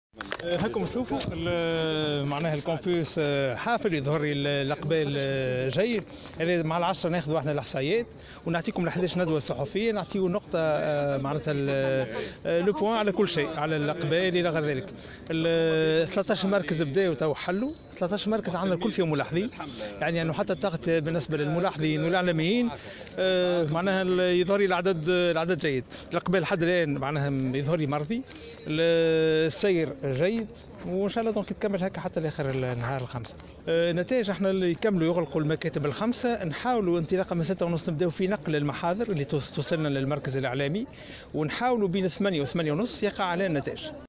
قال رئيس الهيئة العليا المستقلة للانتخابات، شفيق صرصار، في تصريح لمراسلة الجوهرة أف أم، إن إقبال الناخبين على التصويت في انتخابات المجلس الأعلى للقضاء في الساعات الأولى يعتبر مرضيا، مشيرا إلى أن عملية الاقتراع تسير بشكل جيد في مراكز الانتخاب الخمسة عشرة.